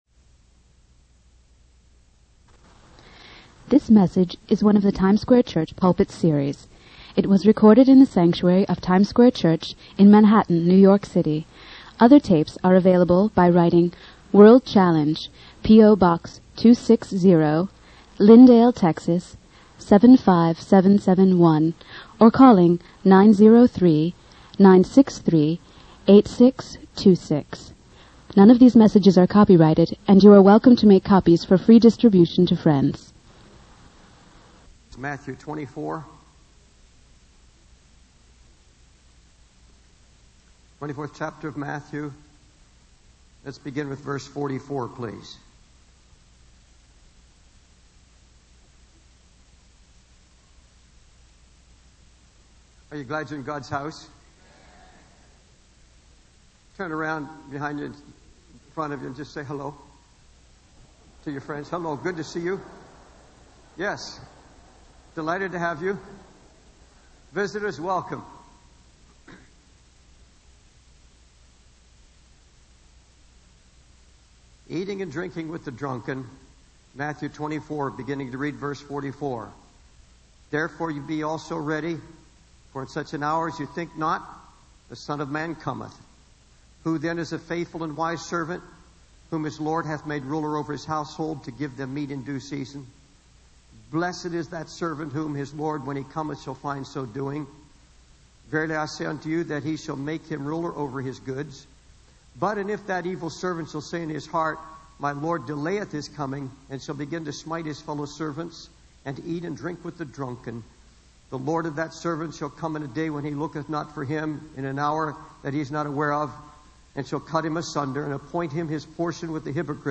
In this sermon, the preacher emphasizes the importance of guarding one's eyes, ears, and diet from sinful influences. He leads the congregation in a prayer of repentance for consuming the wrong food and drink, asking Jesus to be their sustenance. The preacher warns against the addictive nature of sports and the dangers of excessive screen time, particularly on the internet.